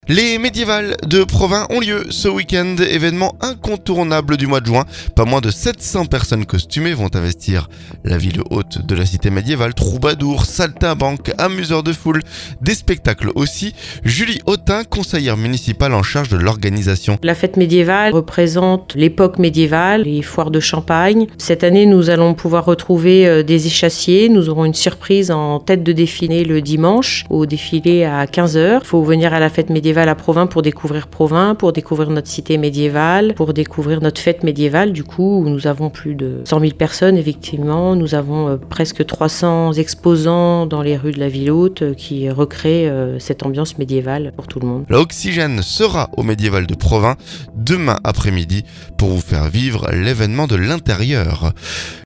Julie Hotin, conseillère municipale en charge de l'organisation.